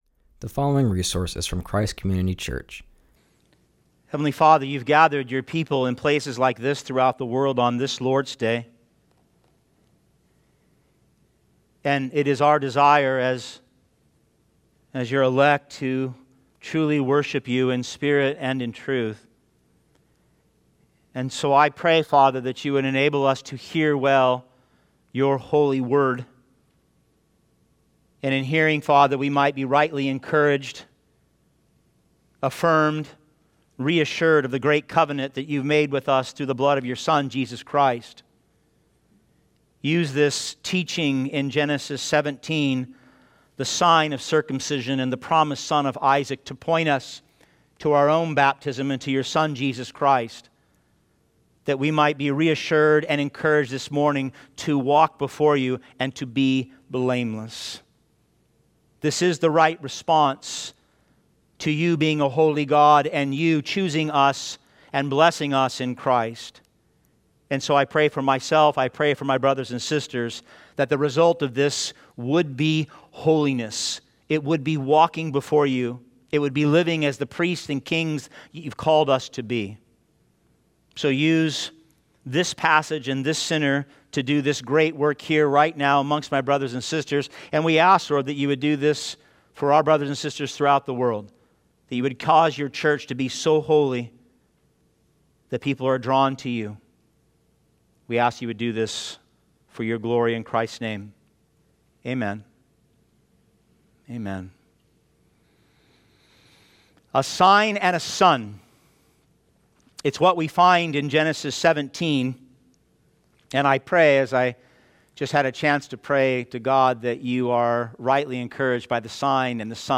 preaches from Genesis 17:1-27.